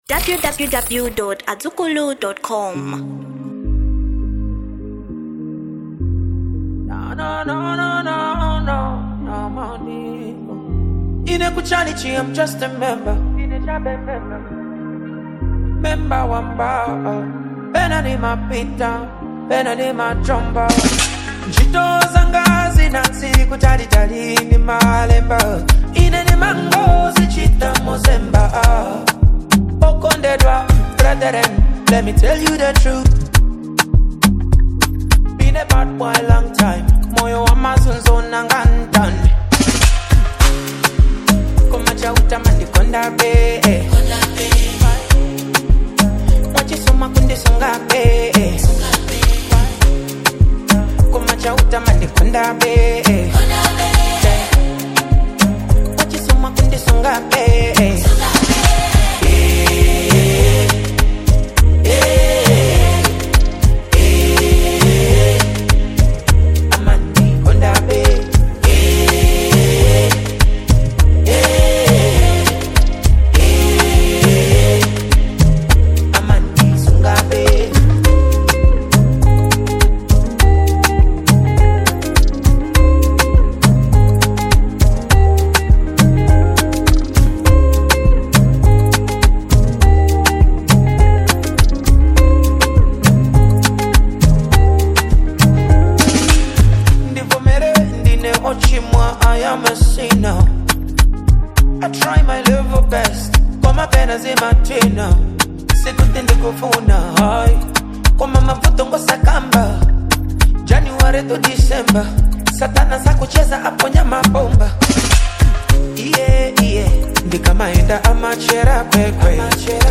Genre Afrobeats